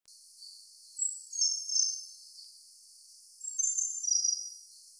35-4溪頭2011黃胸青鶲s3.mp3
黃胸青鶲 Ficedula hyperythra innexa
南投縣 鹿谷鄉 溪頭
錄音環境 森林
鳥叫
Denon Portable IC Recorder 型號 DN-F20R